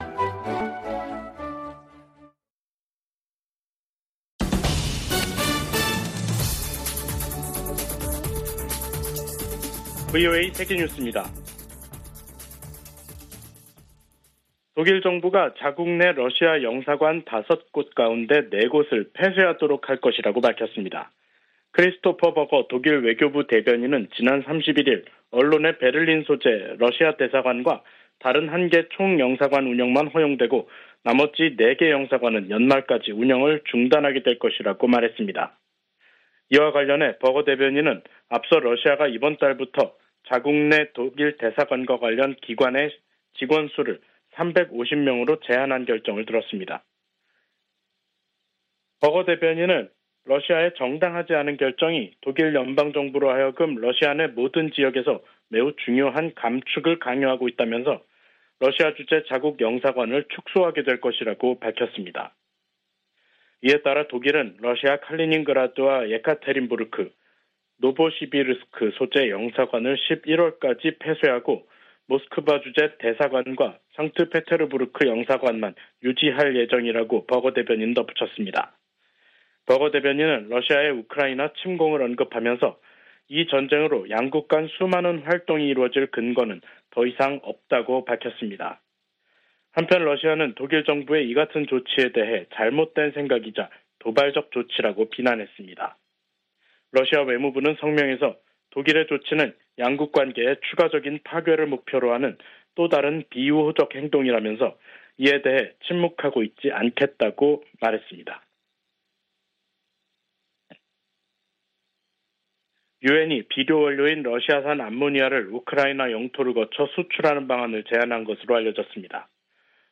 VOA 한국어 간판 뉴스 프로그램 '뉴스 투데이', 2023년 6월 1일 3부 방송입니다. 북한은 정찰위성 성공 여부와 관계 없이 군사 능력을 계속 발전시킬 것이라고 백악관 대변인이 말했습니다. 김여정 북한 노동당 부부장은 자신들의 군사정찰위성 발사를 규탄한 미국을 비난하며 위성 발사를 계속 추진하겠다고 밝혔습니다. 우주의 평화적 이용을 논의하는 유엔 회의에서 북한의 정찰위성 발사를 규탄하는 목소리가 나왔습니다.